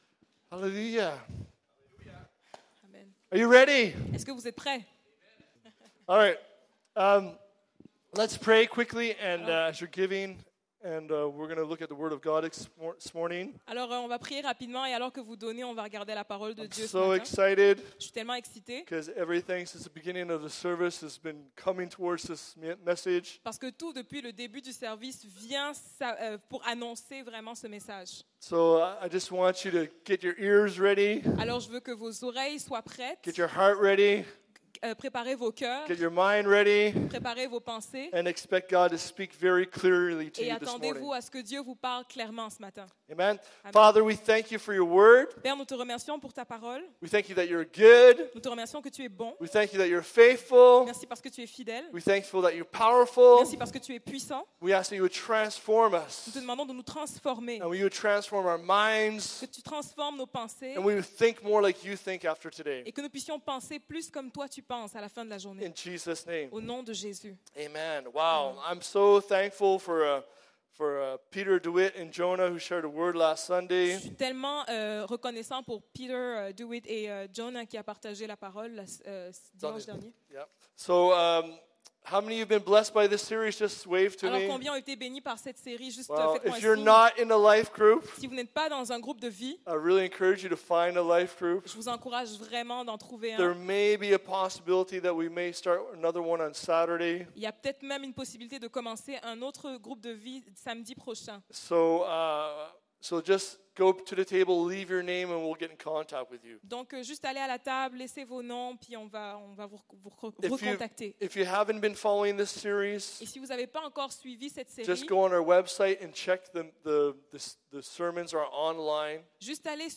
Sermons | Evangel Pentecostal Church